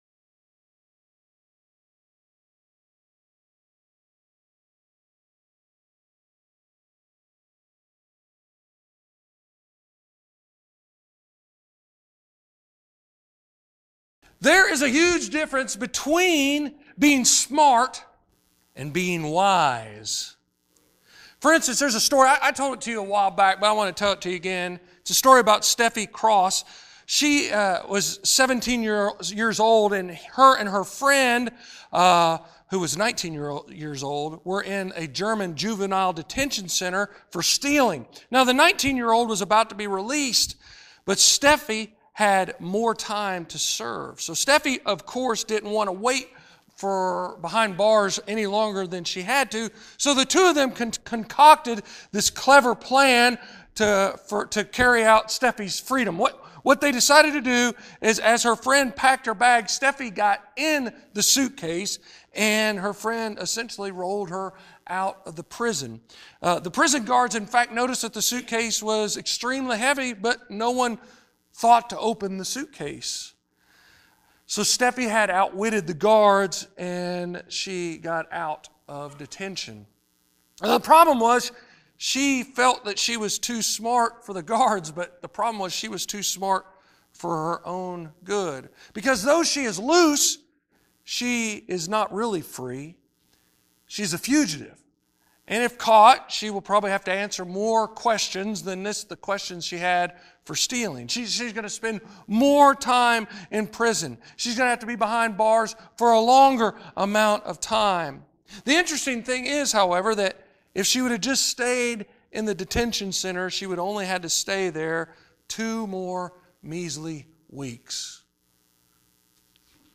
26:57 Sermons in this series The Cross Is Our Victory!